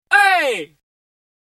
HEY（23KB）